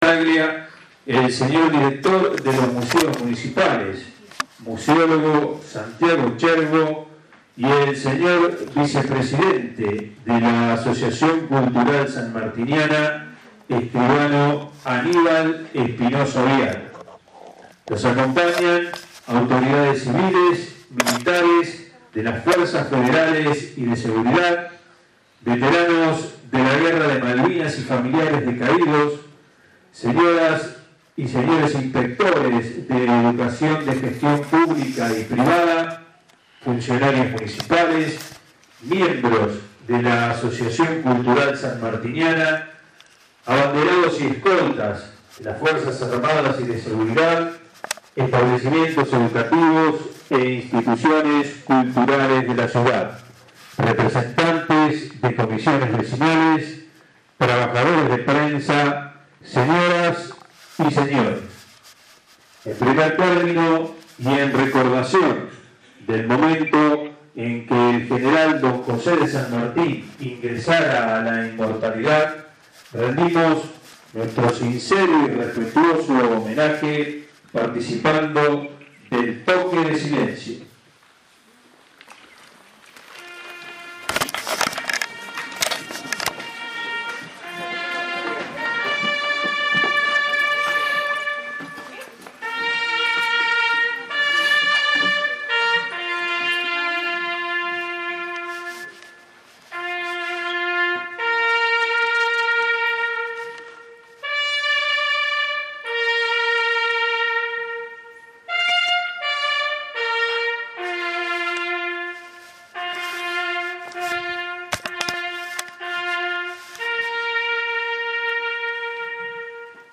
Se realizó ayer las 15.00 hs en el Auditorio Municipal José Félix Bogado sito en Maipú 22 el Acto Central a en conmemoración del Centésimo Sexagésimo Séptimo aniversario del Paso a la Inmortalidad del General Don José de San Martín con la formación de abanderados y escoltas
Audio: Del Acto.